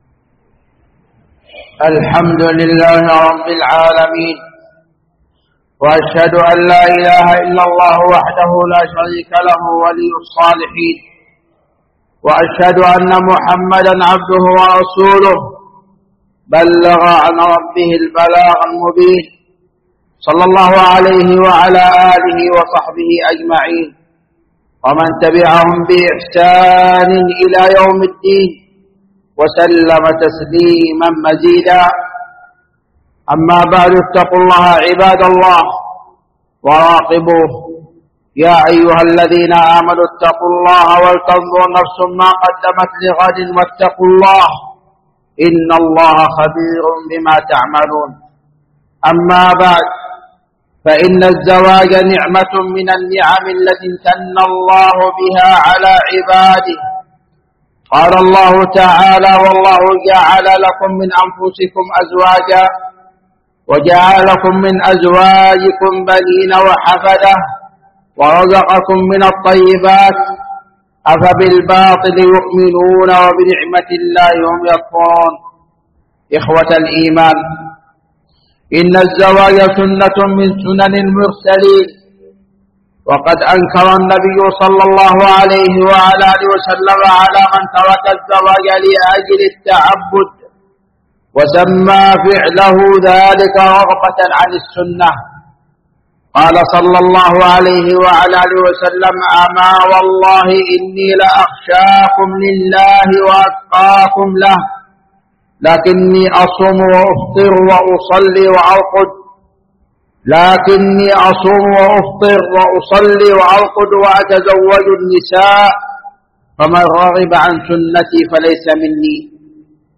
خطبة
جامع الملك عبدالعزيز باسكان الخارش بصامطة